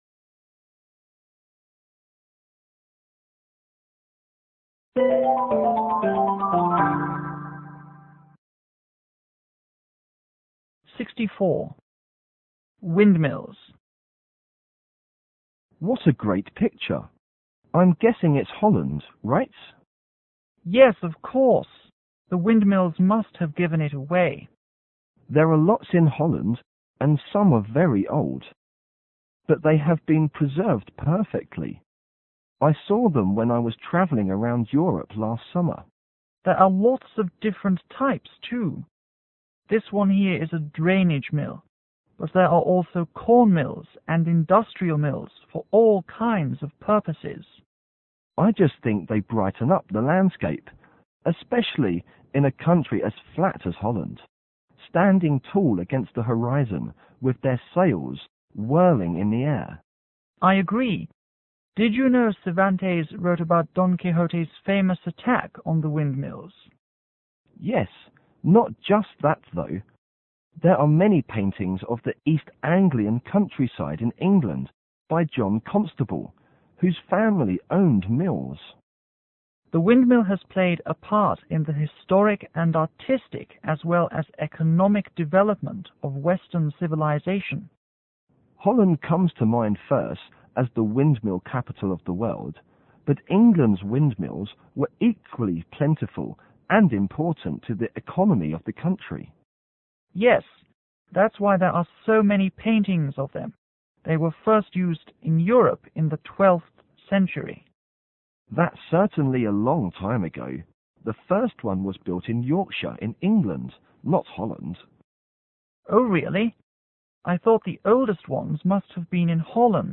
M1 : Man l       M2 : Man 2